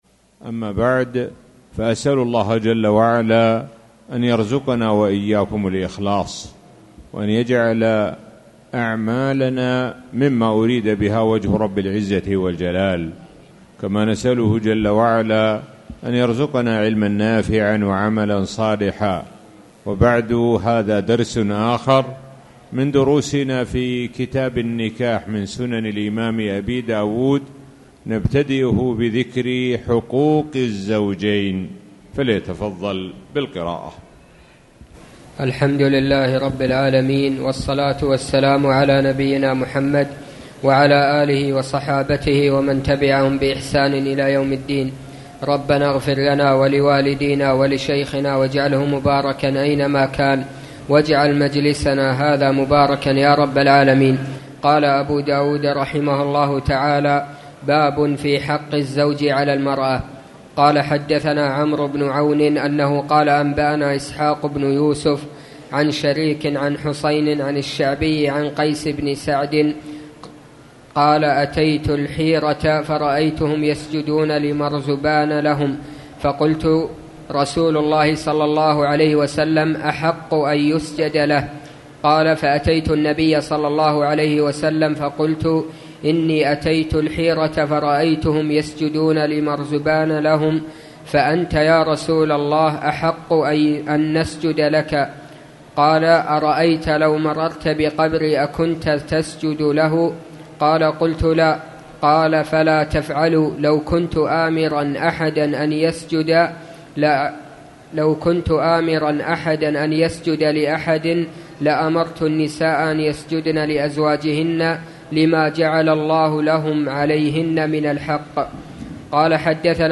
تاريخ النشر ٢٩ شوال ١٤٣٨ هـ المكان: المسجد الحرام الشيخ: معالي الشيخ د. سعد بن ناصر الشثري معالي الشيخ د. سعد بن ناصر الشثري كتاب النكاح